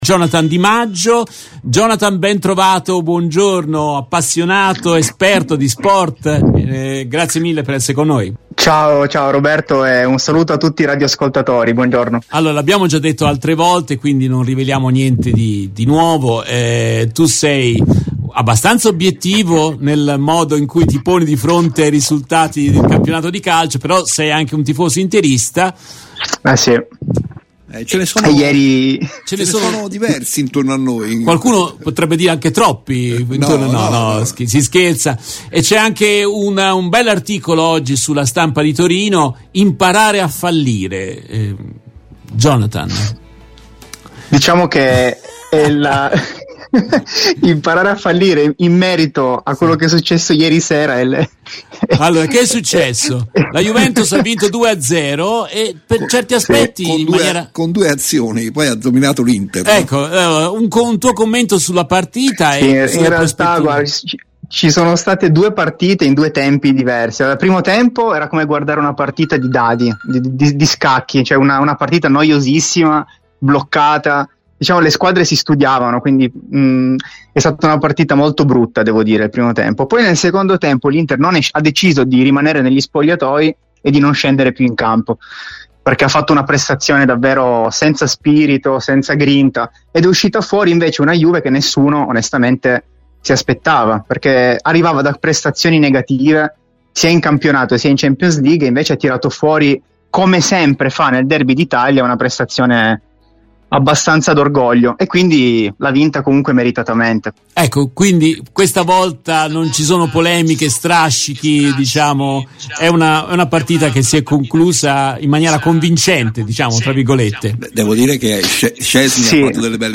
Nel corso della trasmissione in diretta del 07 novembre 2022